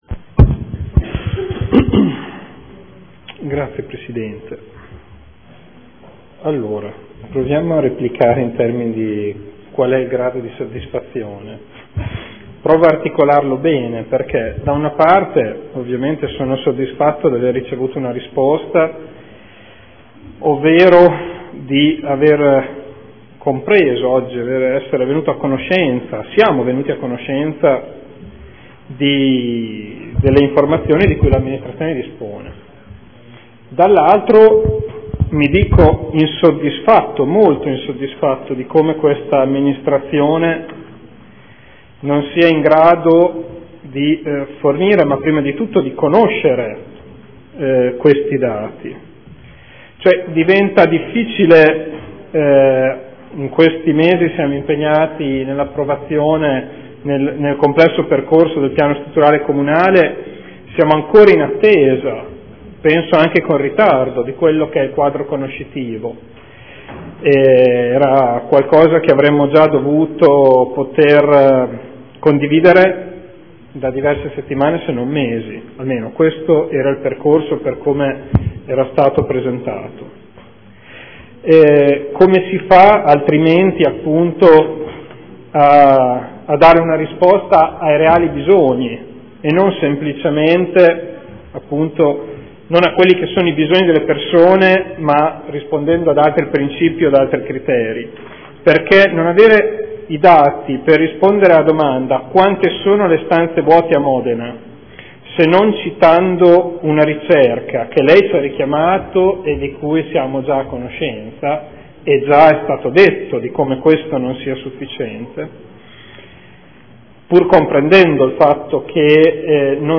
Seduta del 23/01/2014. Replica. Interrogazione del gruppo consiliare SEL avente per oggetto: “Emergenza casa”